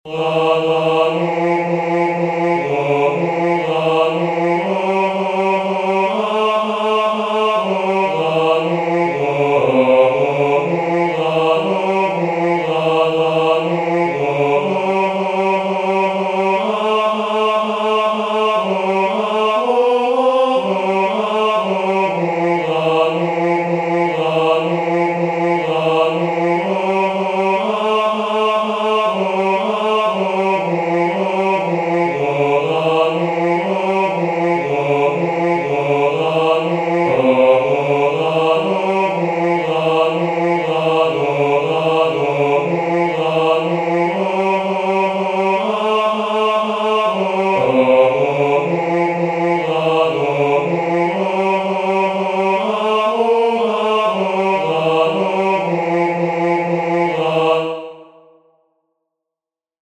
The first chant of Rauner 003203 folio 015v "Isti sunt triumphatores," the third responsory from the second nocturn of Matins, Common of Apostles
"Isti sunt triumphatores," the third responsory from the second nocturn of Matins, Common of Apostles